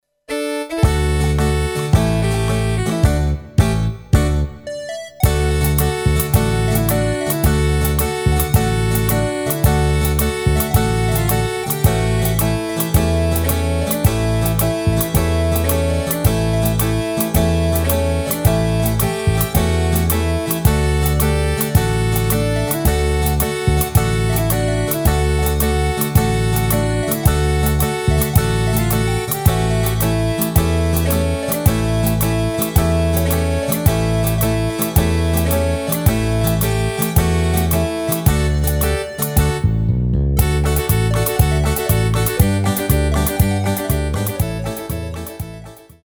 Rubrika: Folk, Country
- parodie